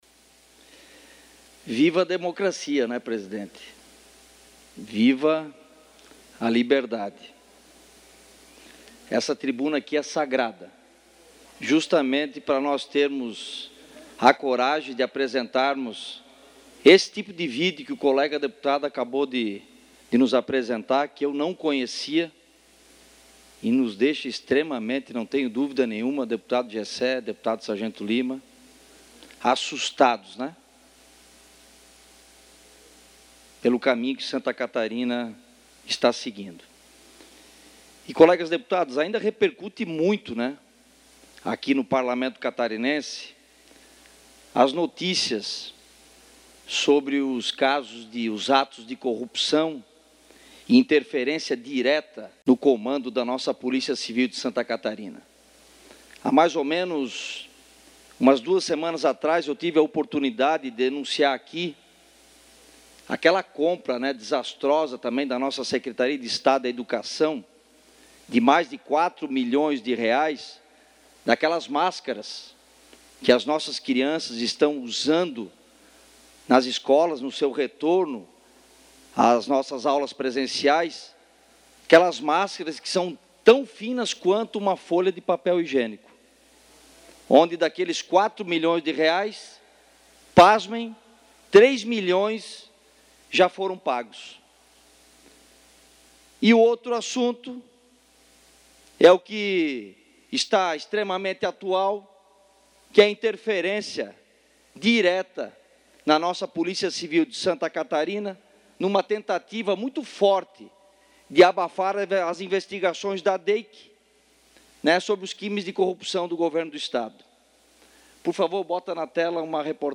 Pronunciamentos da sessão ordinária desta terça-feira (05)
Confira os pronunciamentos dos deputados na sessão ordinária desta terça-feira (05):